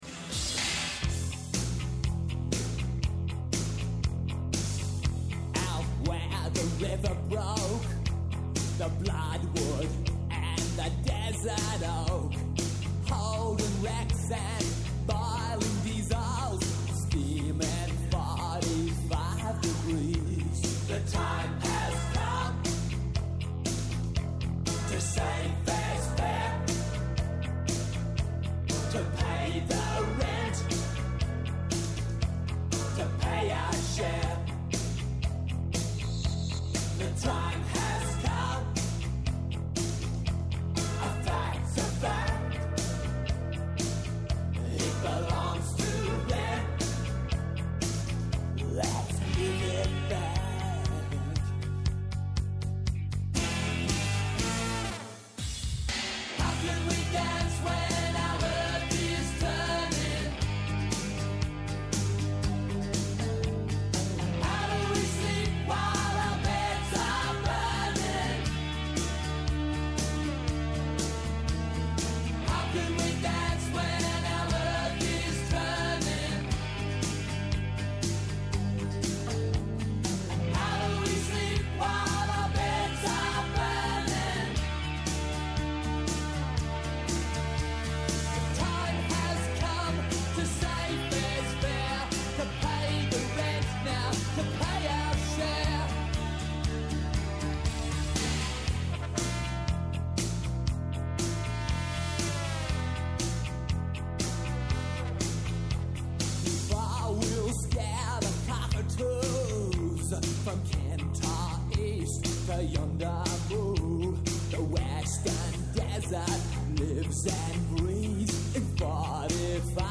Στην σημερινή εκπομπή καλεσμένοι :
-Ο Δημήτρης Καιρίδης, υπουργός Μετανάστευσης και Ασύλου, καθηγητής Διεθνών Σχέσεων στο Πάντειο Πανεπιστήμιο